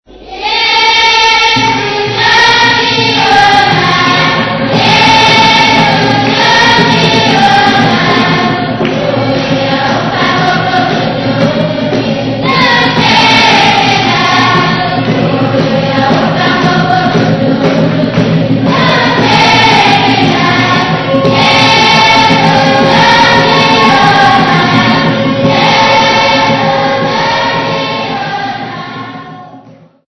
School children
Folk music
Church music
Field recordings
sound recording-musical
Indigenous music.
7.5 inch reel